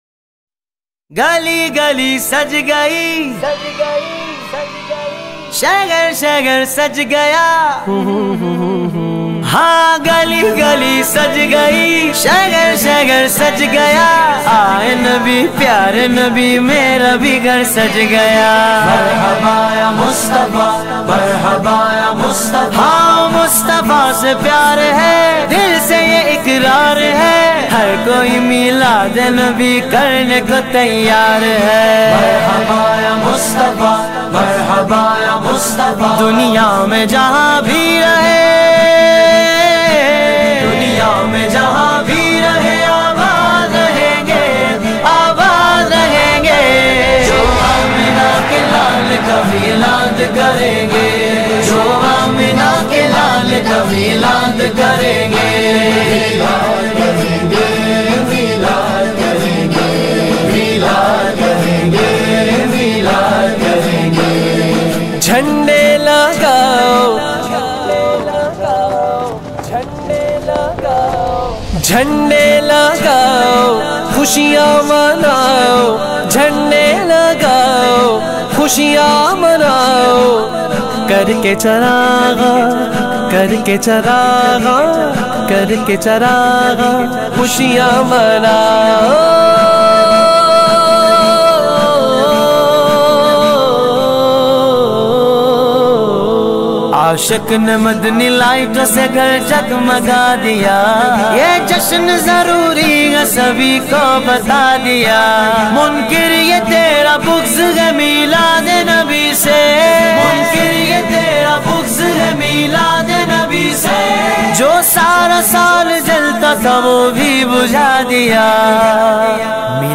Naat Sharif
in a Heart-Touching Voice